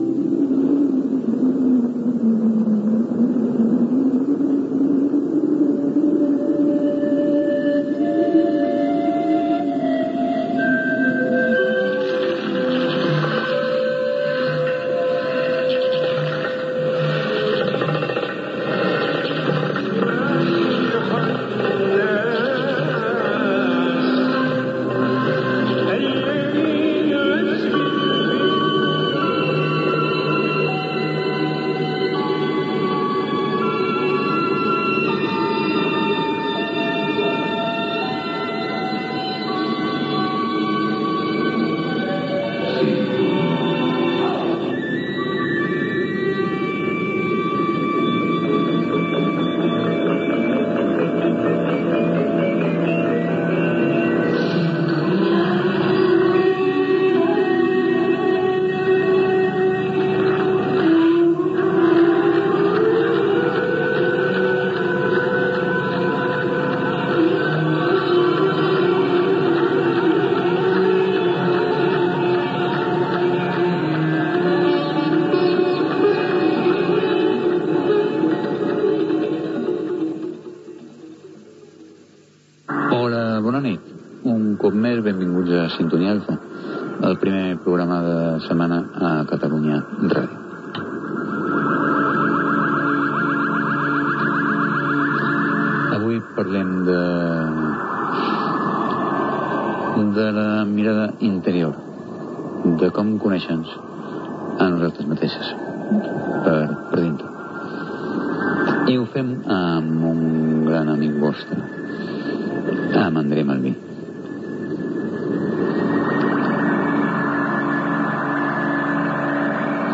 Últim programa. Sintonia del programa i presentació de l'invitat